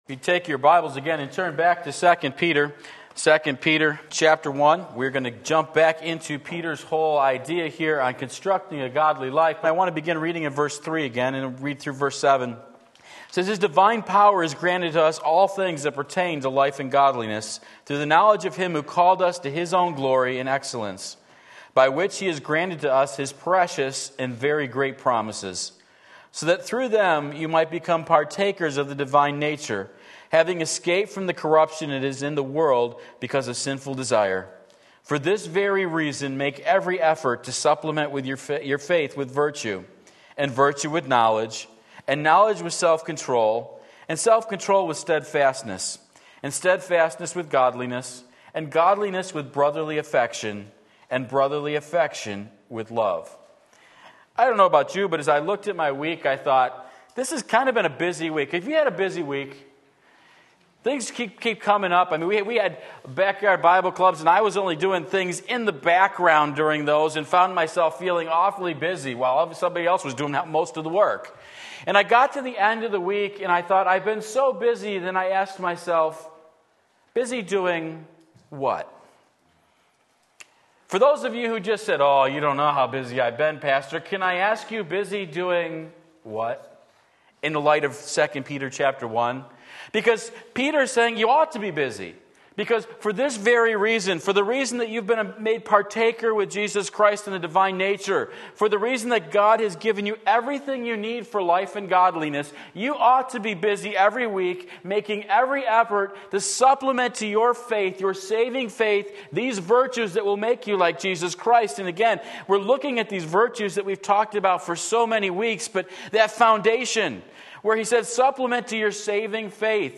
Sermon Link
Brotherly Affection 2 Peter 1:7 Sunday Morning Service, July 21, 2019 Stirred Up!